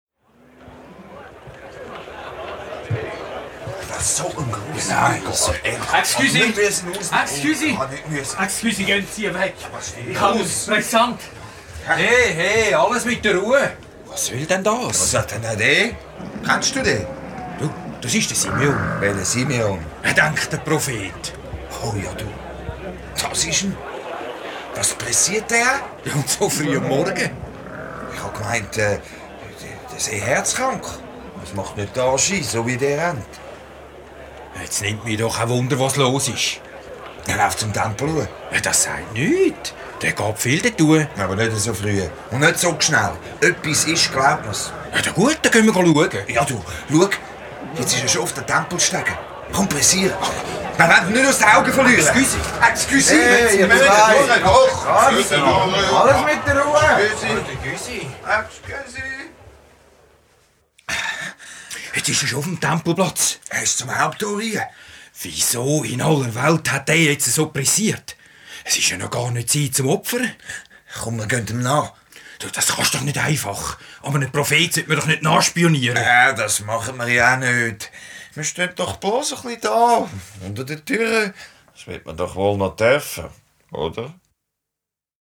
Hörspiel ab 6 Jahren